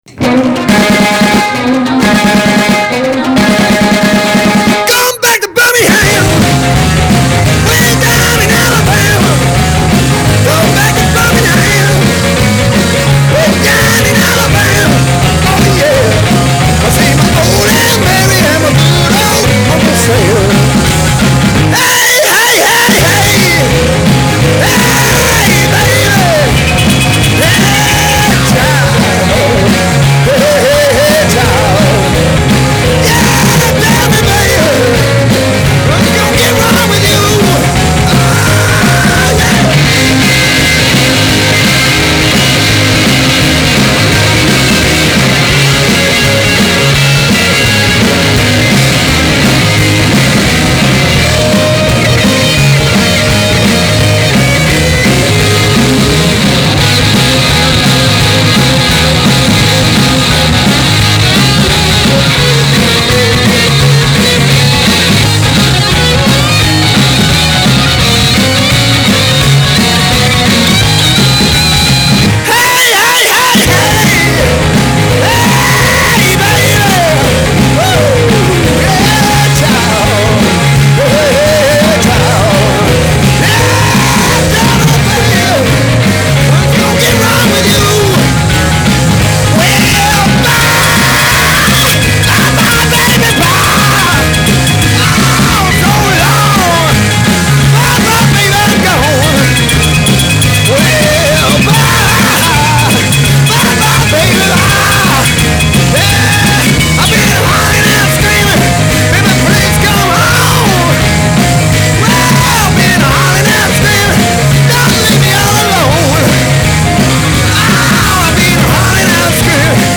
Blues guitar, slapping piano and classic 60’s soul.